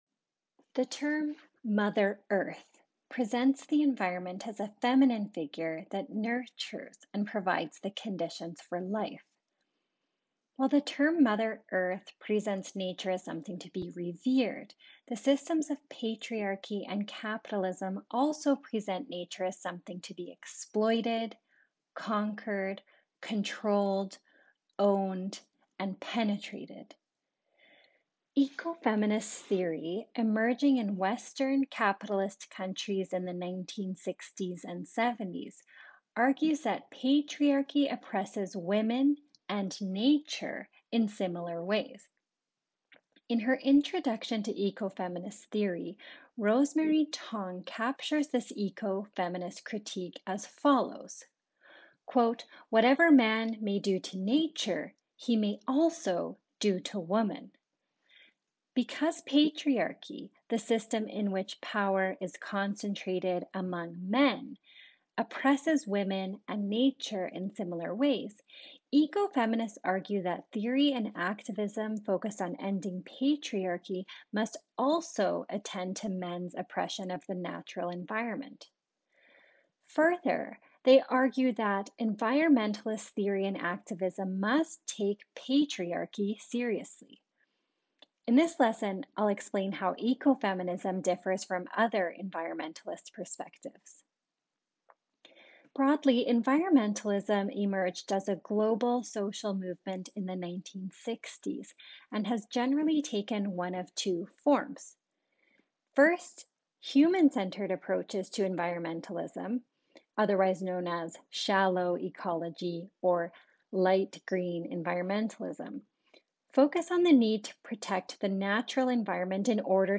Ecofeminism lecture transcriptDownload